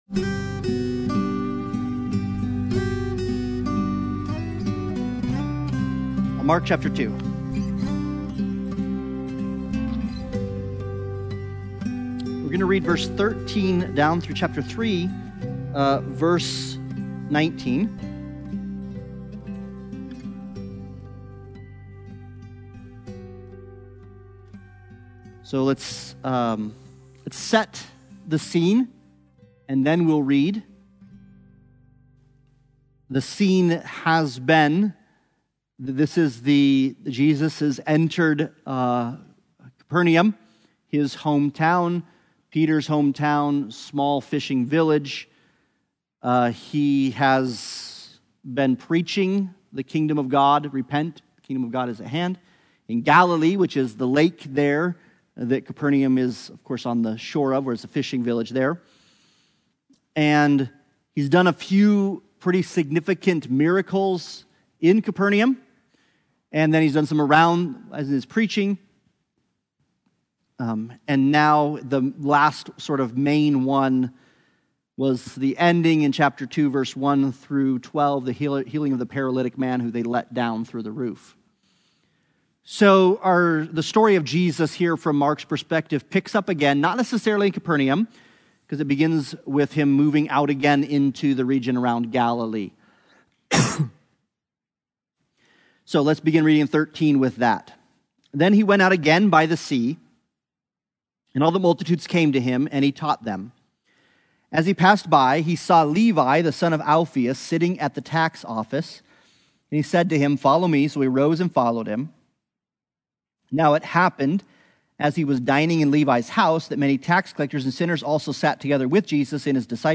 The Gospel According to Mark Service Type: Sunday Bible Study « A Word from Grandpa